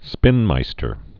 (spĭnmīstər)